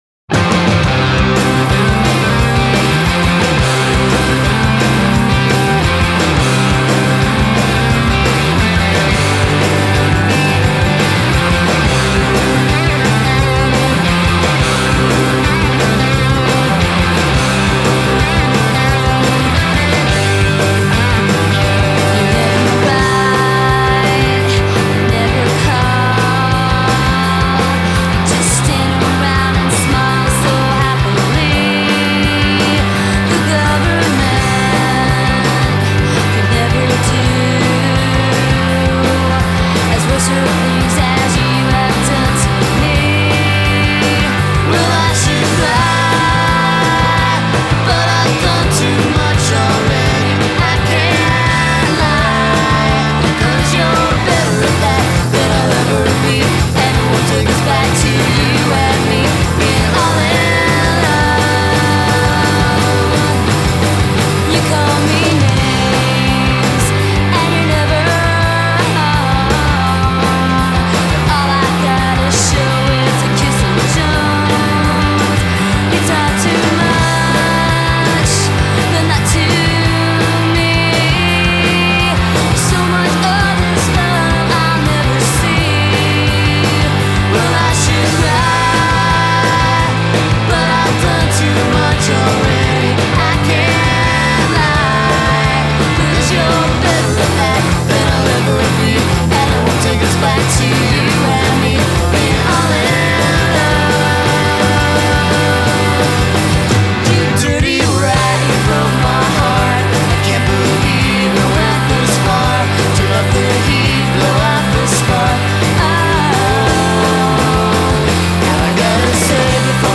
alt-pop-rock